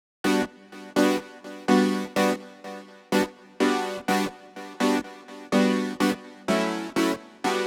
32 Synth PT1.wav